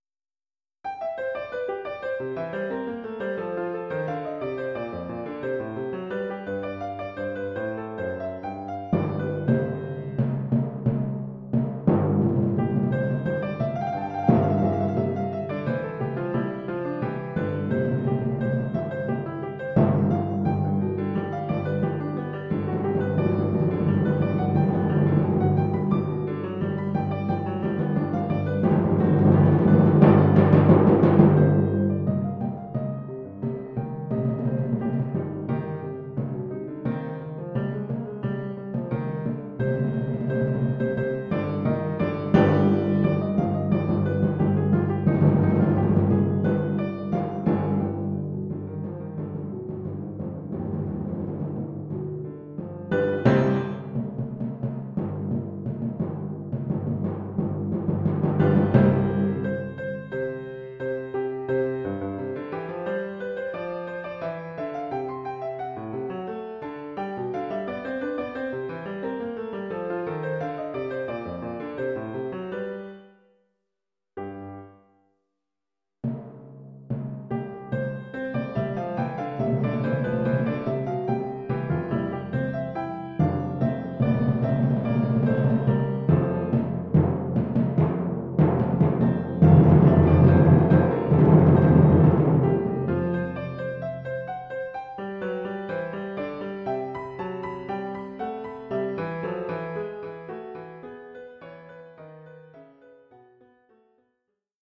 Collection : Percussions
Oeuvre pour deux timbales avec
accompagnement de piano.
Dans le style de Bach.